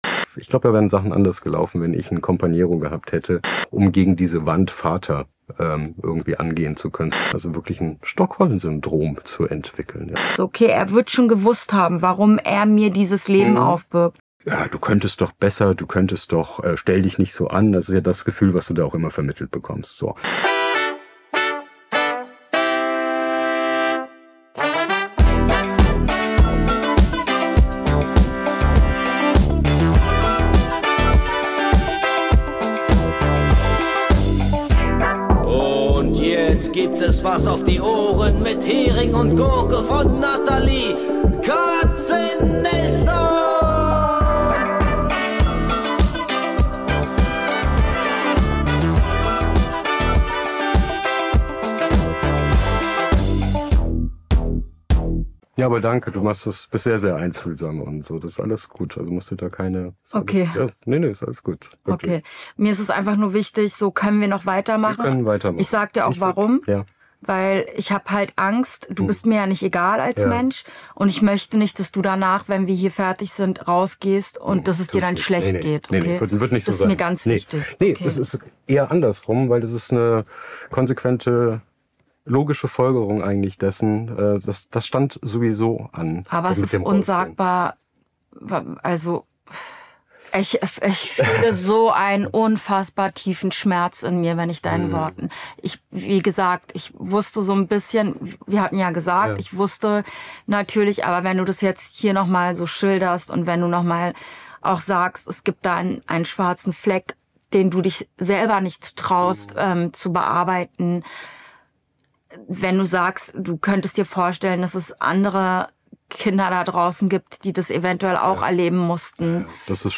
Aber das hier ist leise.
Sondern ein Gespräch darüber, was passiert, wenn dich deine eigene Geschichte einholt.